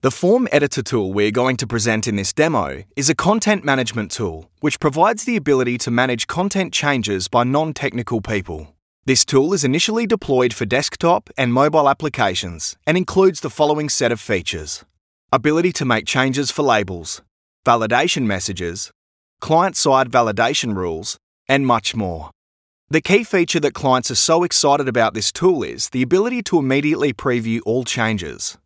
Male
English (Australian)
Adult (30-50)
My voice although very versatile has been described as warm, engaging, Aussie slang, natural, professional, confident, strong, professional and friendly to name a few.
Audiobooks
1203Audiobook_Script.mp3